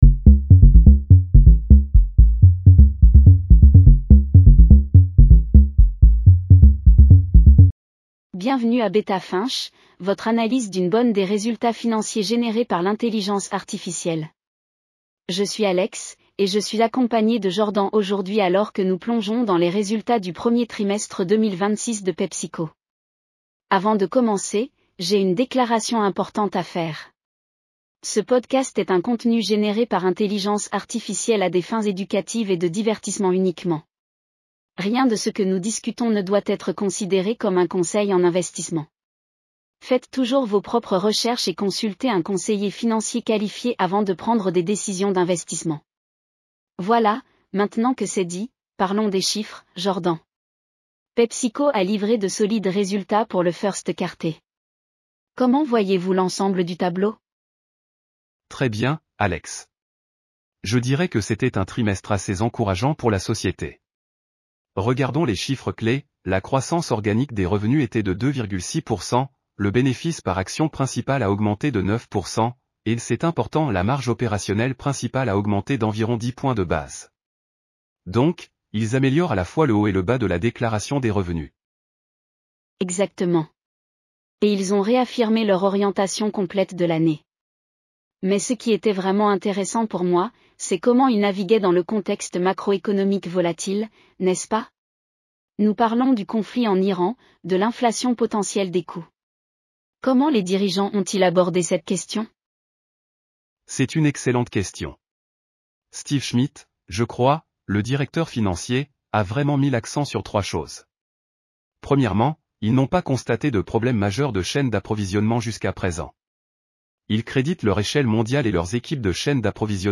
PepsiCo Q1 2026 earnings call breakdown.